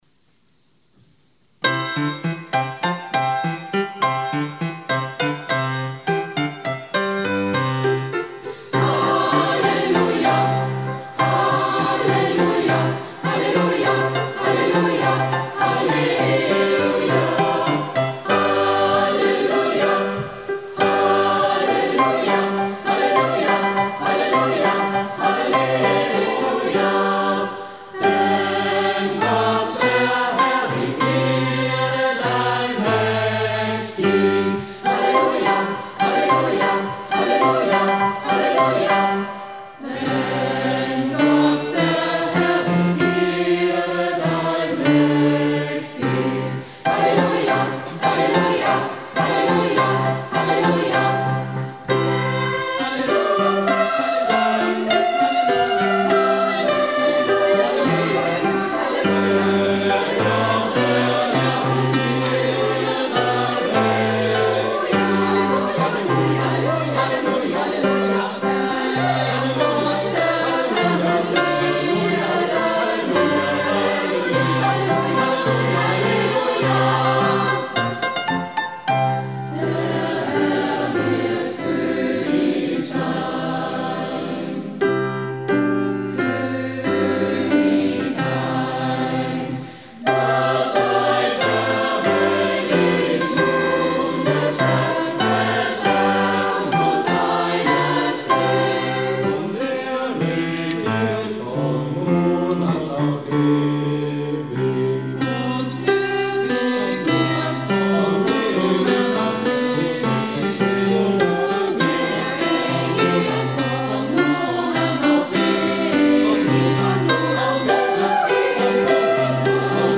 Am 18. Oktober 2024 fand um 19:30 im Festsaal der Arbeiterkammer das große HERBSTKONZERT statt.